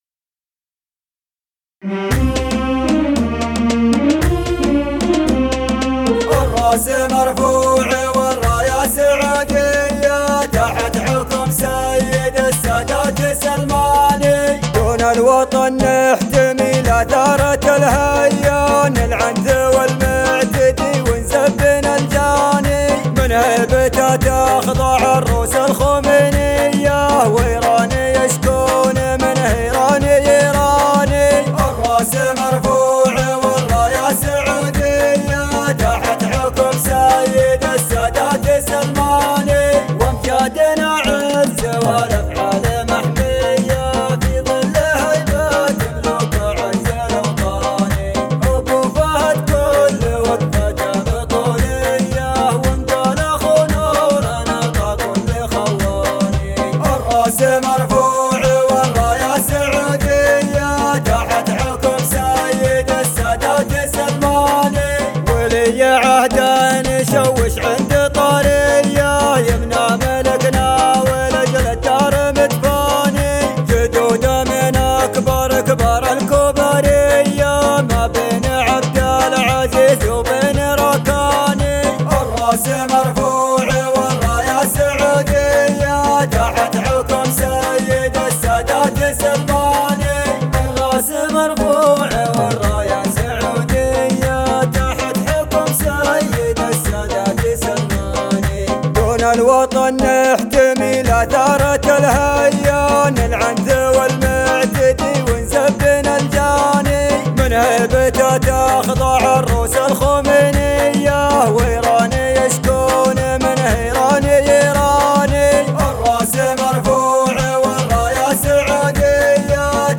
عجم